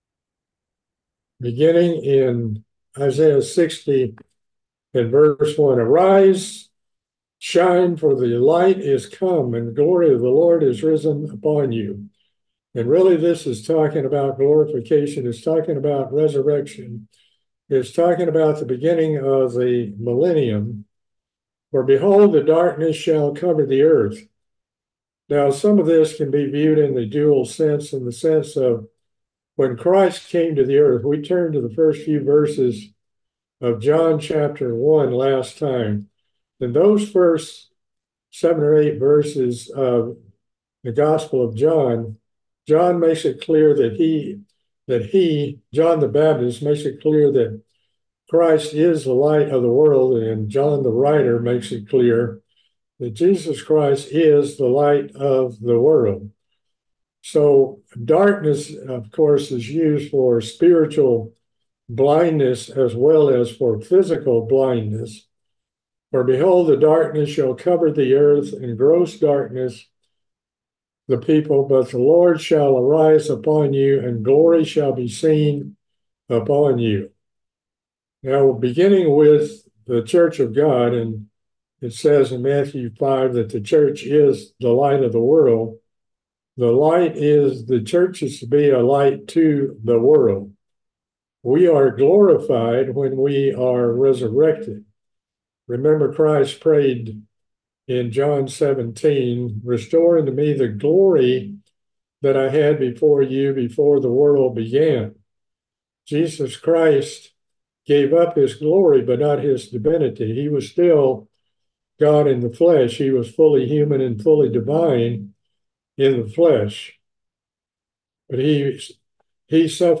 We continue with our Bible Study of the book of Isaiah. We pickup in chapter 60 and continue through chapter 62.